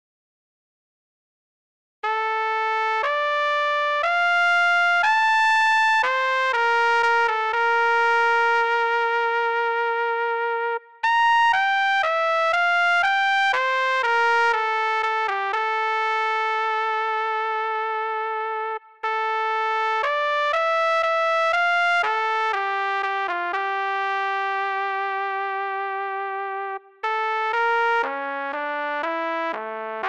GSi Mini Orchestra 是基于精选的长循环高质量立体声样本，有助于轻松快速地再现交响乐团的声音。
它提供 9 个部分，包括：低音铜管乐器（大号、长号）、大提琴和低音提琴、圆号、木管乐器（长笛、双簧管、fagotto）
高音铜管乐器（小号和长号）、高音弦乐器（小提琴、中提琴）、合唱、钟琴和打击乐器（定音鼓和铙钹）。